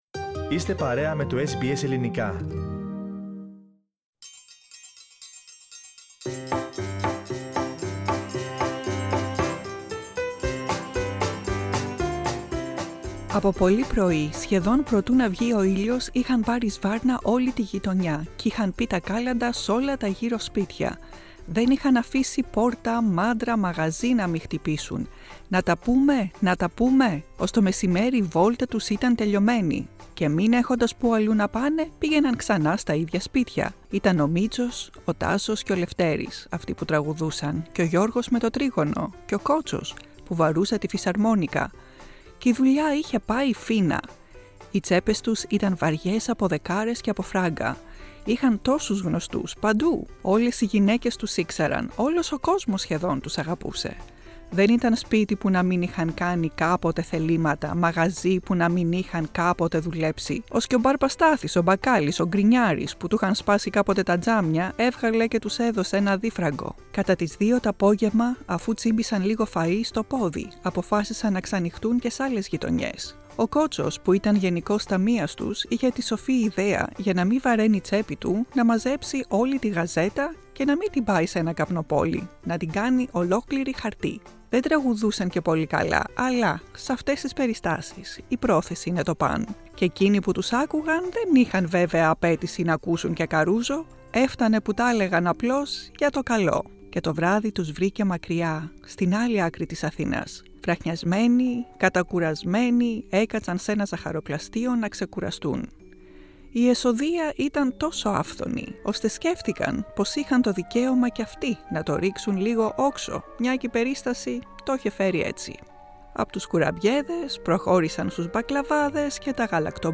Produced and narrated